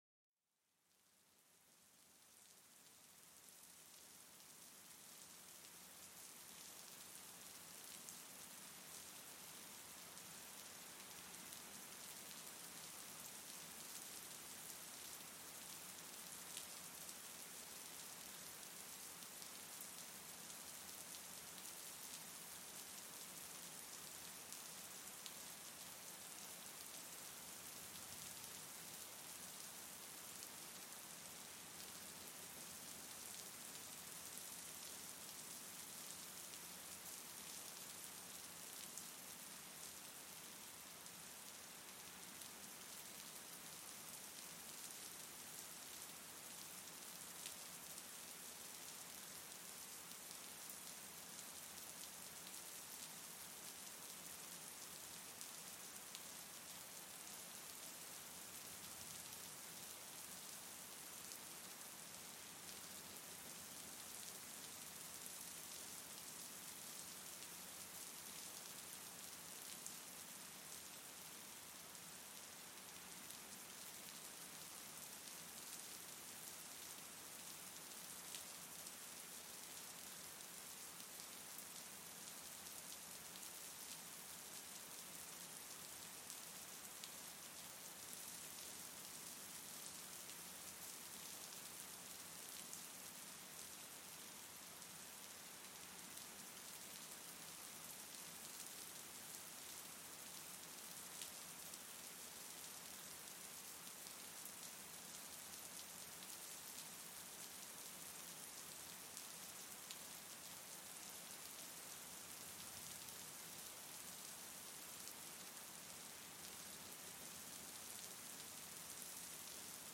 En este episodio, déjate arrullar por el suave murmullo de la lluvia cayendo pacíficamente sobre el suelo. El sonido calmante de las gotas de lluvia crea una atmósfera perfecta para la relajación y el sueño.